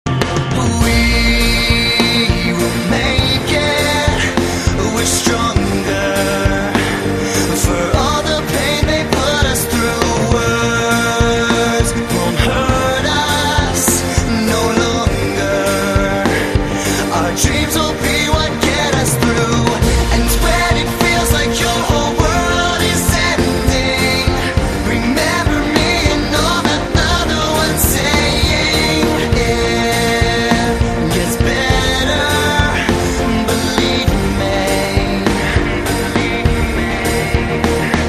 M4R铃声, MP3铃声, 欧美歌曲 112 首发日期：2018-05-15 06:58 星期二